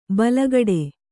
♪ balagaḍe